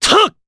Clause_ice-Vox_Attack3_jp.wav